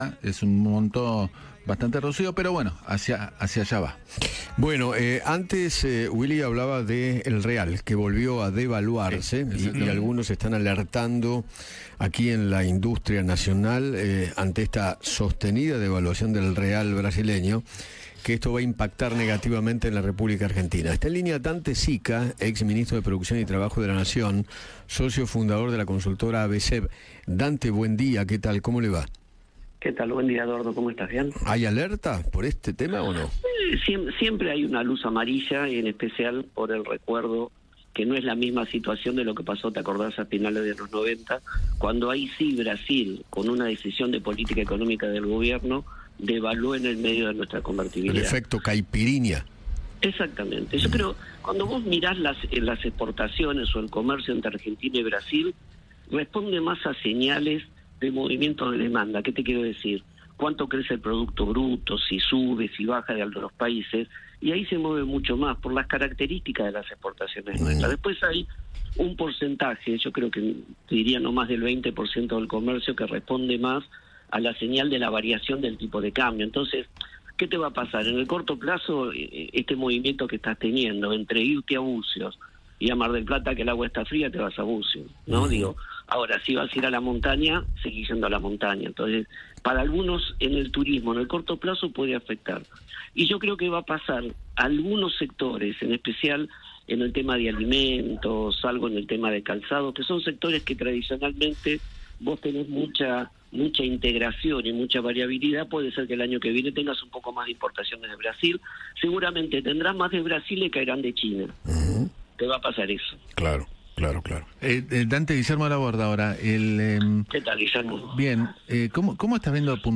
El ex ministro de Producción y Trabajo, Dante Sica, conversó con Eduardo Feinmann sobre la devaluación del real brasileño y cómo afecta a la Argentina.